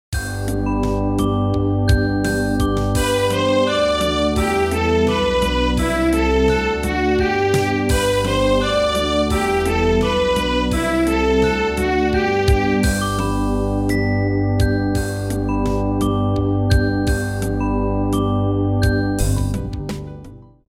quite fast